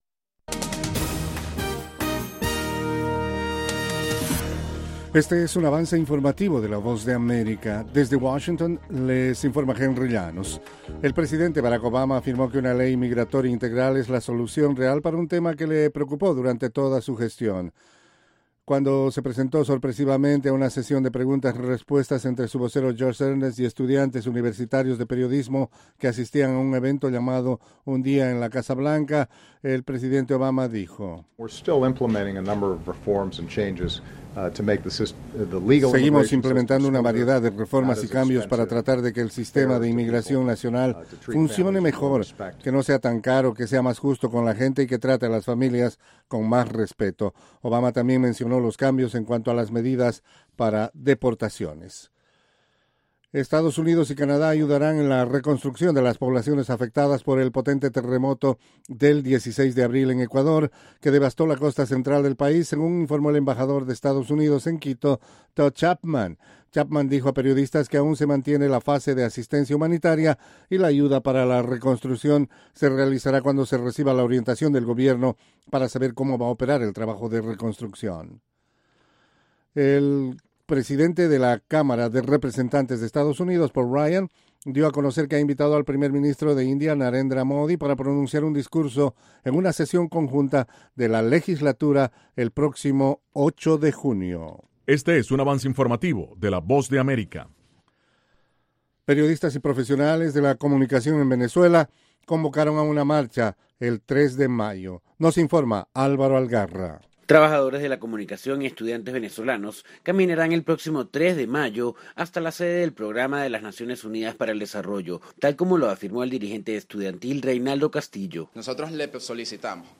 Capsula informativa de tres minutos con el acontecer noticioso de Estados Unidos y el mundo.